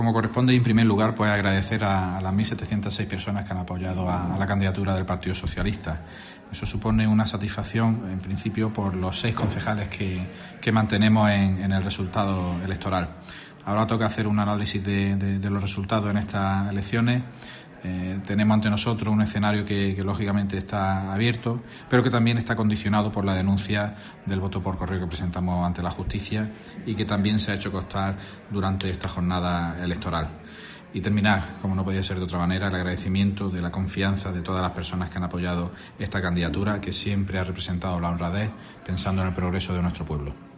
AUDIO: Alcalde de Carboneras, José Luis Amérigo (PSOE).